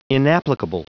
Prononciation du mot inapplicable en anglais (fichier audio)
Prononciation du mot : inapplicable